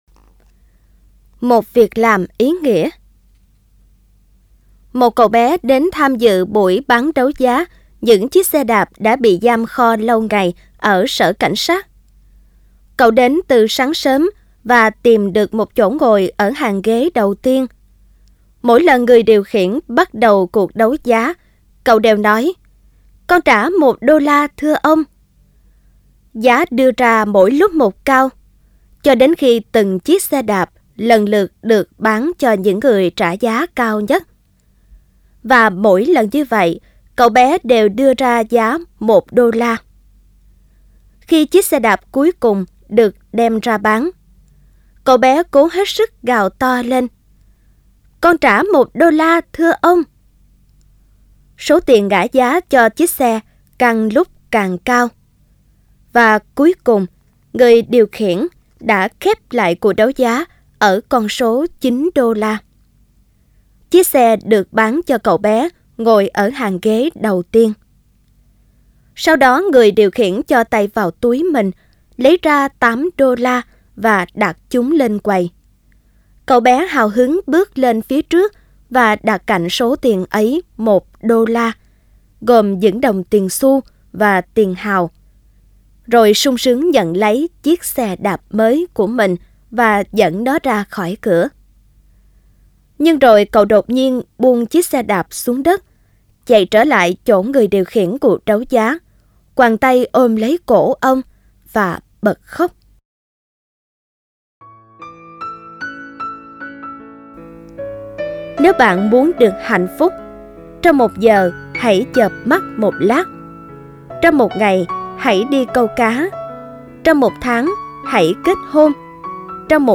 Sách nói Chicken Soup 4 - Chia Sẻ Tâm Hồn Và Quà Tặng Cuộc Sống - Jack Canfield - Sách Nói Online Hay